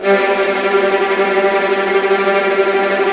Index of /90_sSampleCDs/Roland L-CD702/VOL-1/STR_Vlas Bow FX/STR_Vas Tremolo